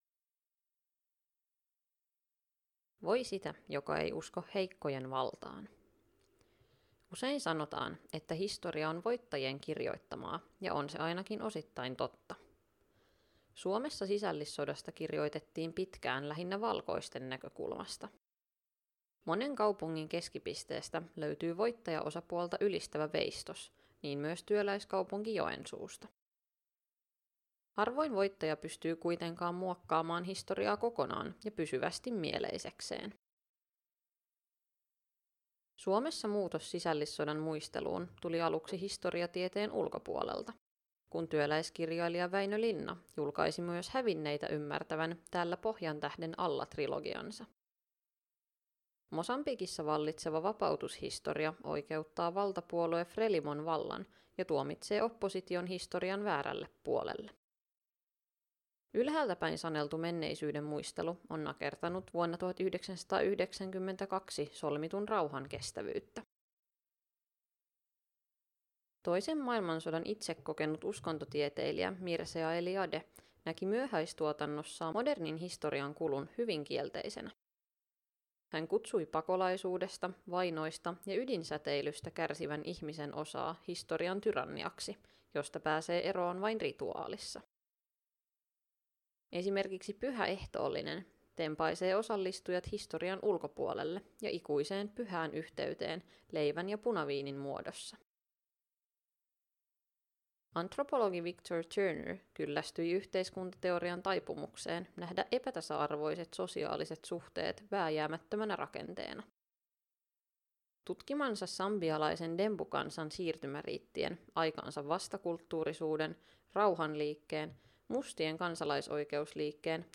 Podcast-lukija